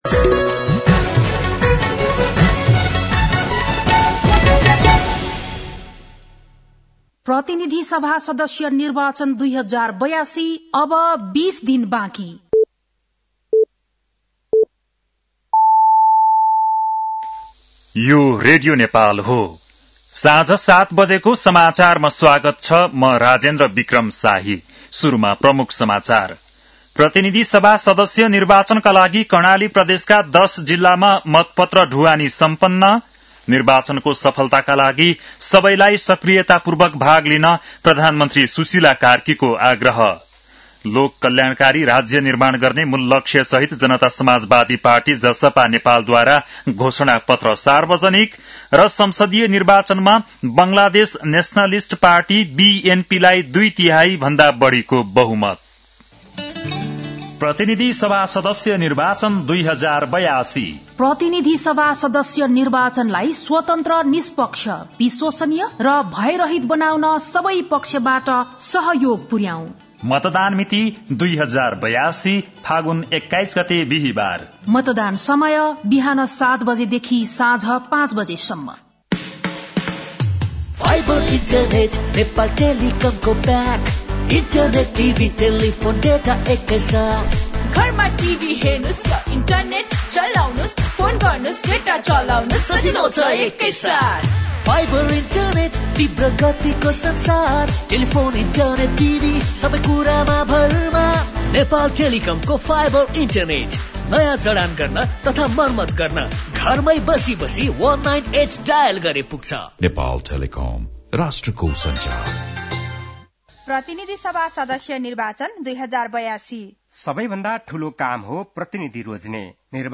बेलुकी ७ बजेको नेपाली समाचार : १ फागुन , २०८२